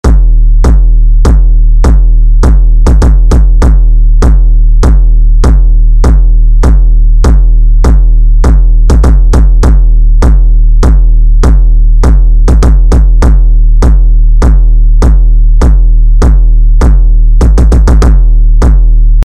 Wenige Kicks entfernt und doch ganz anders: